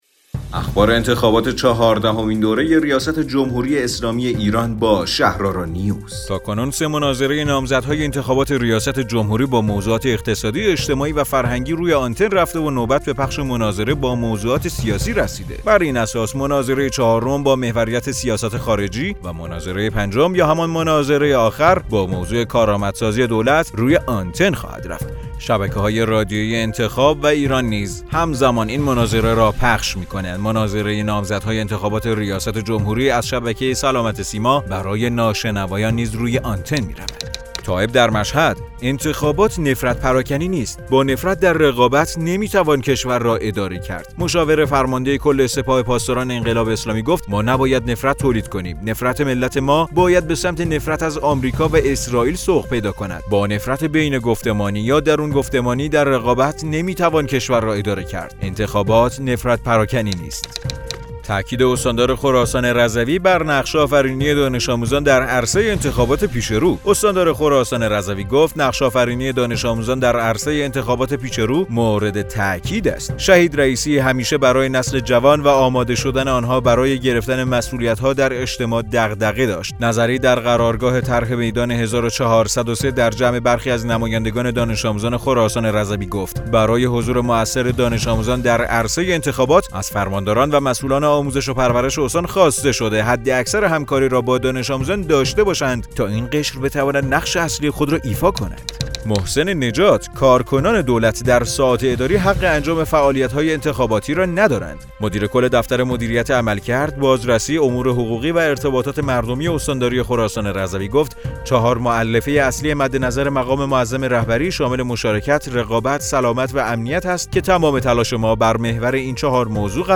رادیو شهرآرا، پادکست خبری انتخابات ریاست جمهوری ۱۴۰۳ است.